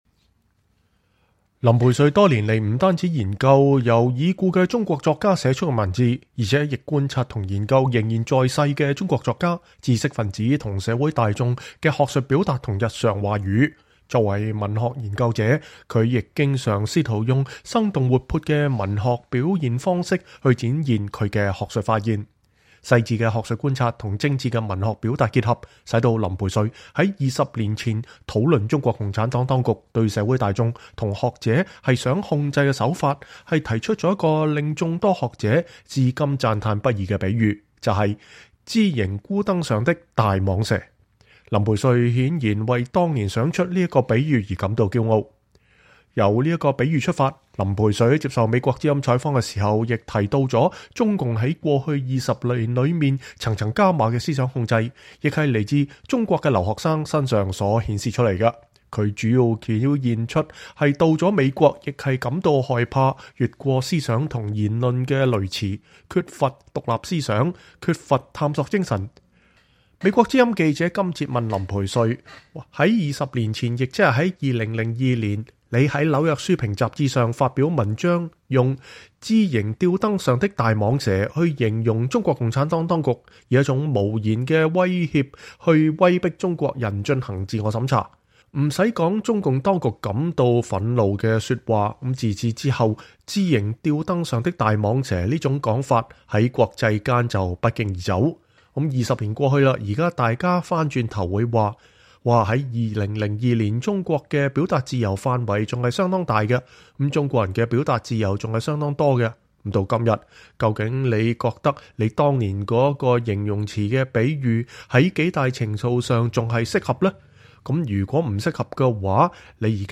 專訪林培瑞（3）：談中共當局的言論控制